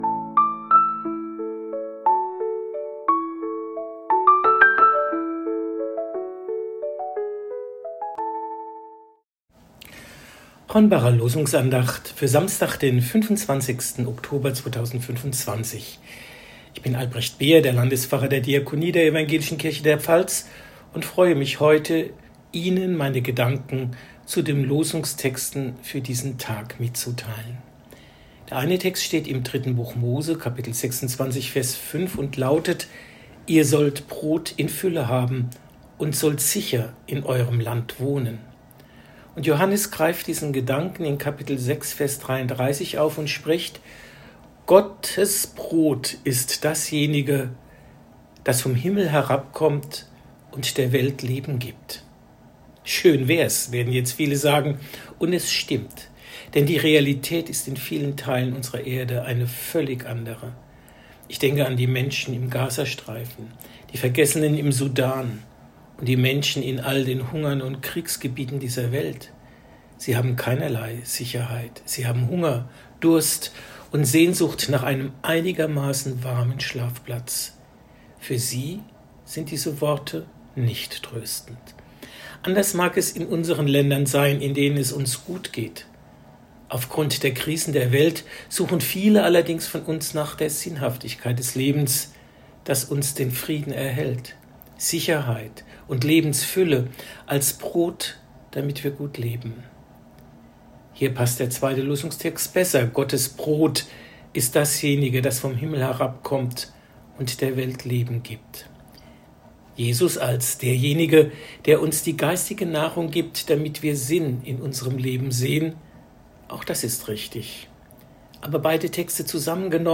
Losungsandachten